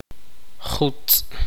[kʲa.’ʛiʛ] sustantivo generalmente no poseido wind